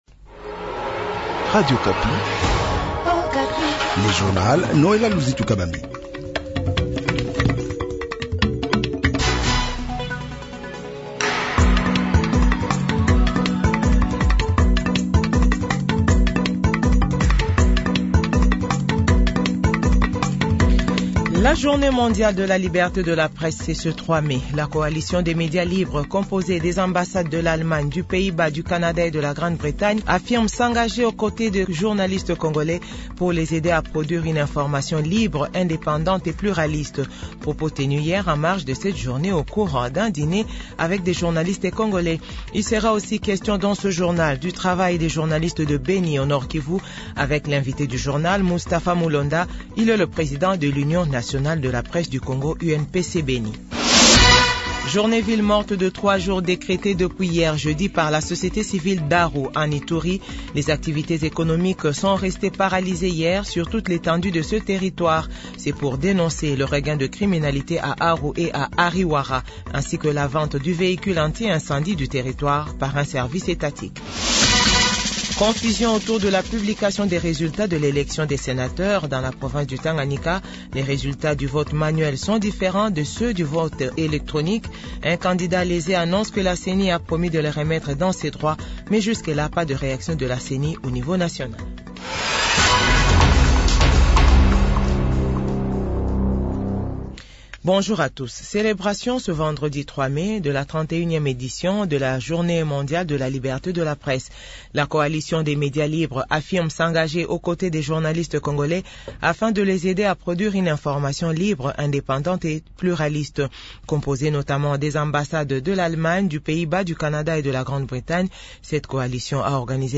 JOURNAL 8H00